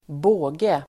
Uttal: [²b'å:ge]